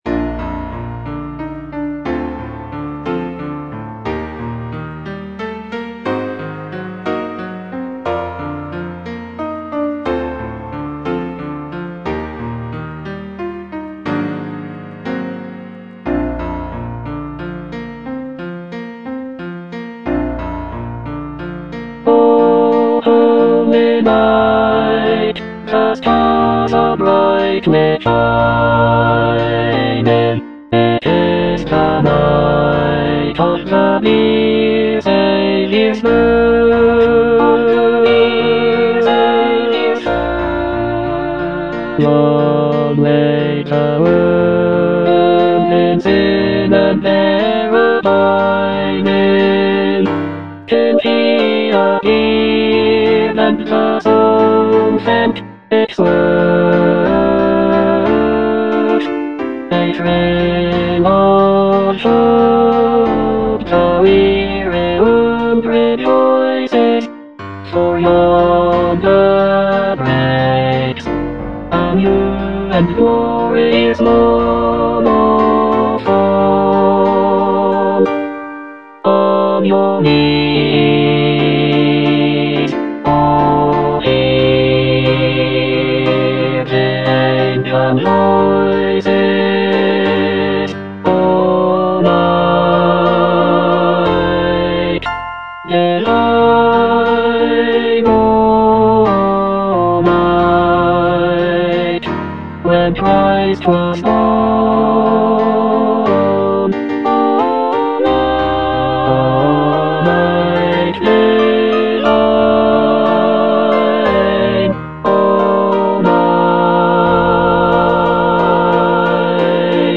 Bass (Emphasised voice and other voices)